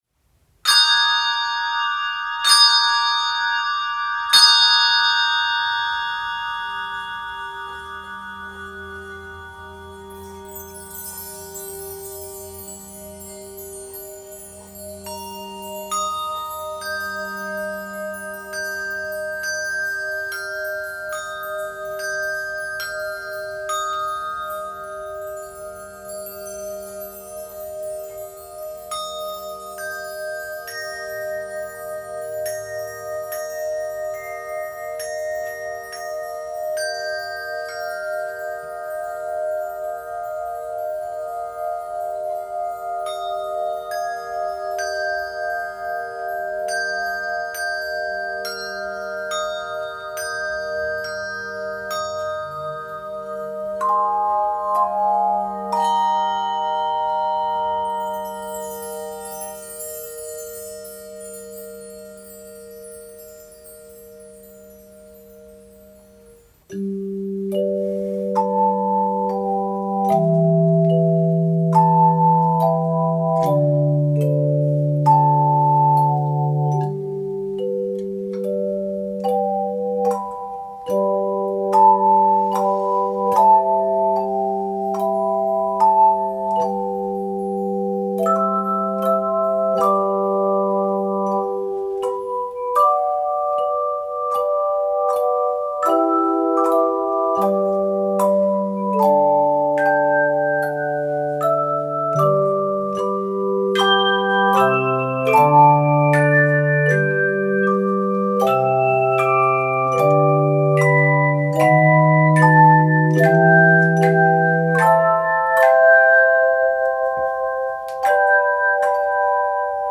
Composer: African American Spiritual